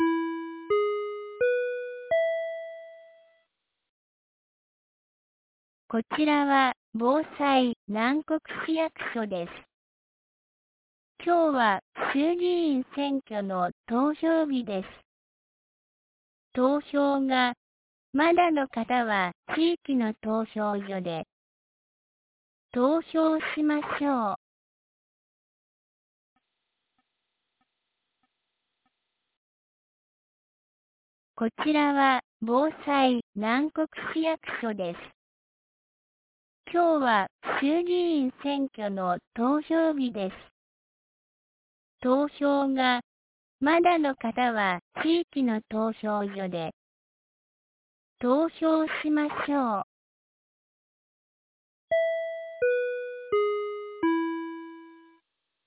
2026年02月08日 10時00分に、南国市より放送がありました。